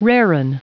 Prononciation du mot raring en anglais (fichier audio)
Prononciation du mot : raring